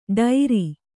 ♪ ḍairi